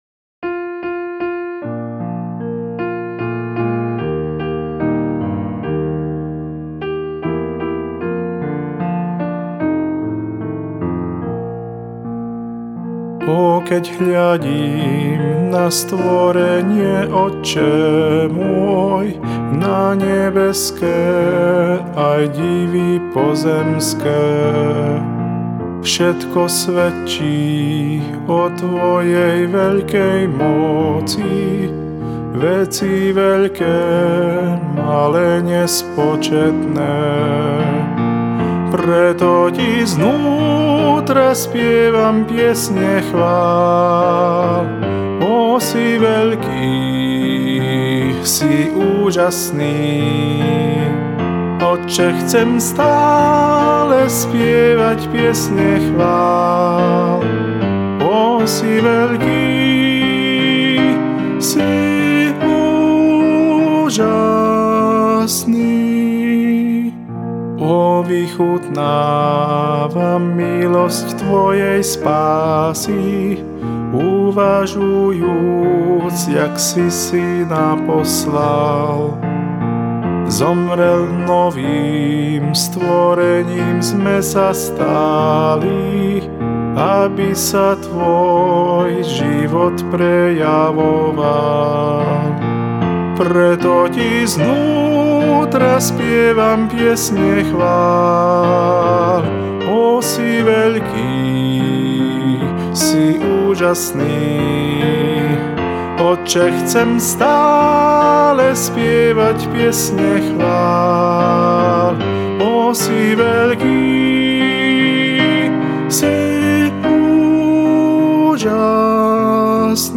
Swedish folk melody
降B大調